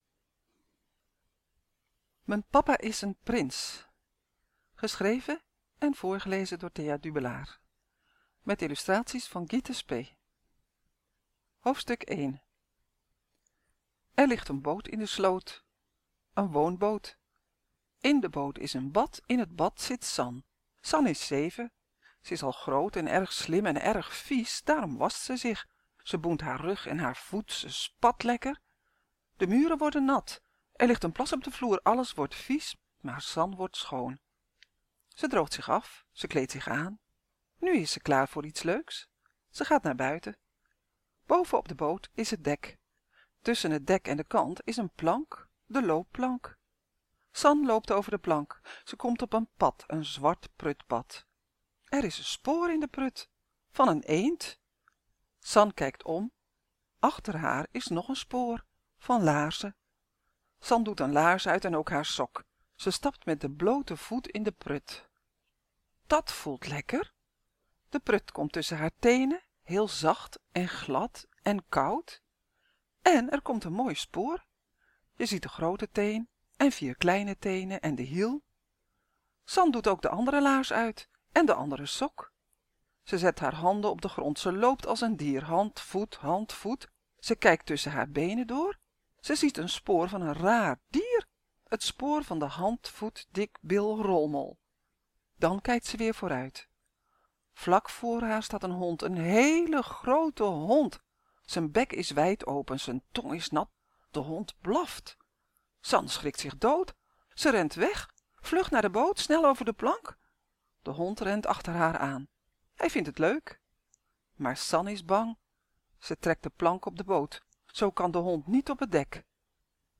Maar in werkelijkheid heeft ze wel een vader. En haar papa is ook nog een echte prins. Nu ook als luisterboek (v.a. 5 jaar en ouder ) Zorg dat je geluid aan staat en klik op de play knop.